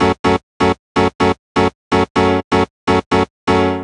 cch_synth_loop_raver_125_F#m.wav